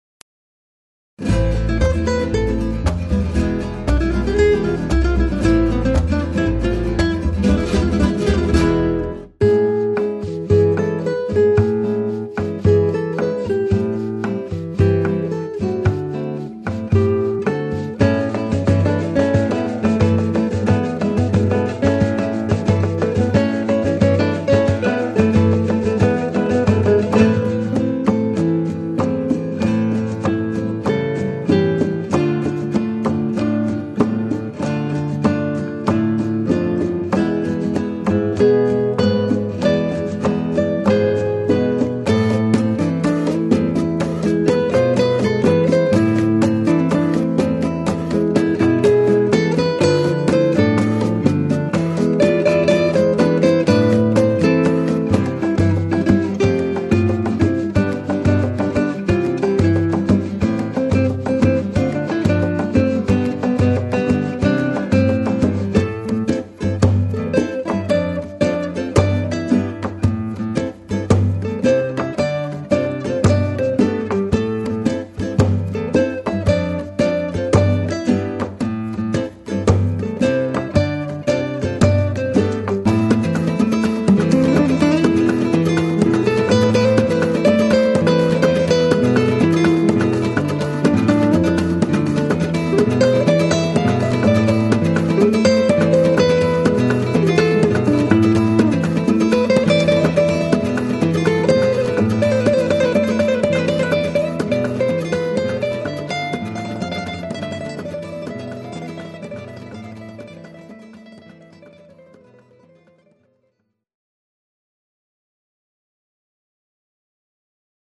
Guitar Percussion
Imitiere mit der Gitarre lateinamerikanische und karibische
Percussion-Instrumente und Rhythmen:
Bossa Nova, Samba, Bolero, Mambo, Salsa, Calypso, Reggae
Best of-Latin-Mix (mp3)